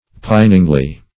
piningly - definition of piningly - synonyms, pronunciation, spelling from Free Dictionary Search Result for " piningly" : The Collaborative International Dictionary of English v.0.48: Piningly \Pin"ing*ly\, adv.
piningly.mp3